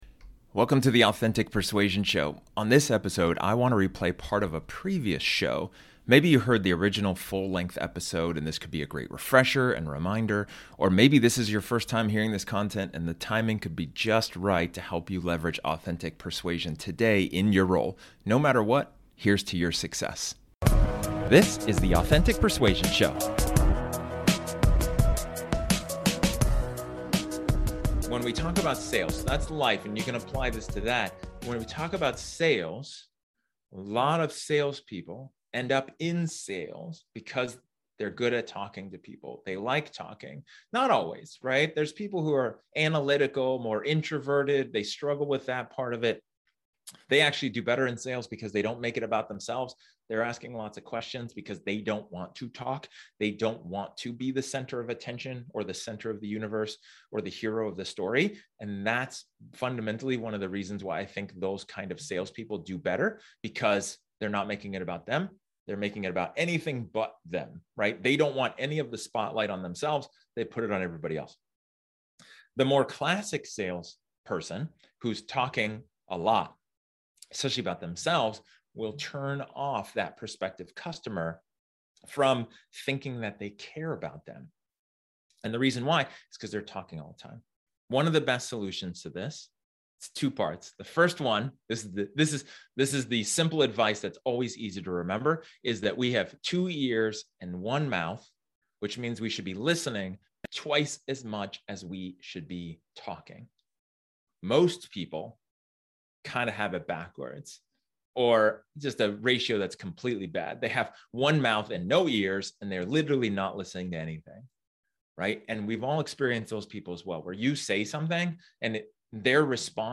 This episode is an excerpt from one of my training sessions where I talk about building rapport.